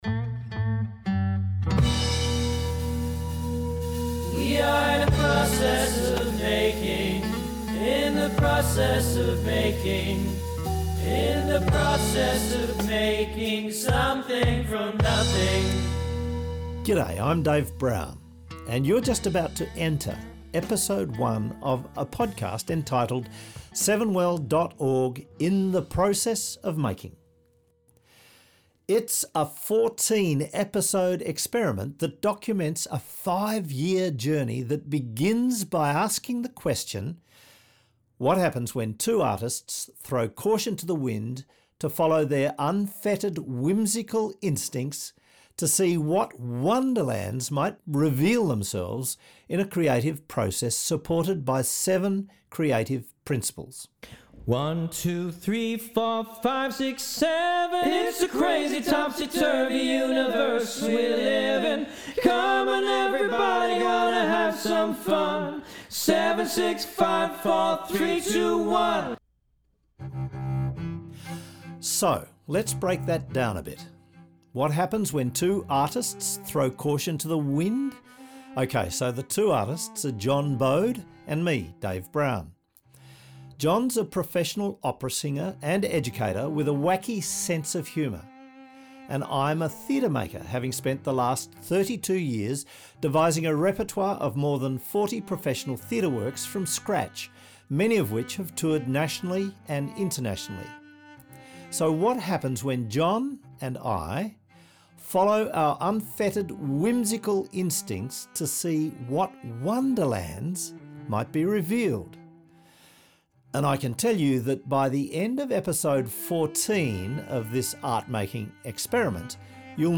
d) The musical snippets and songs (the music is catchy and adds to the podcast)
__ a) Added energy and kept things moving